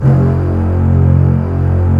Index of /90_sSampleCDs/Roland L-CD702/VOL-1/STR_Cbs Arco/STR_Cbs1 f